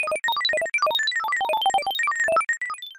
data-processing-sound-effect.mp3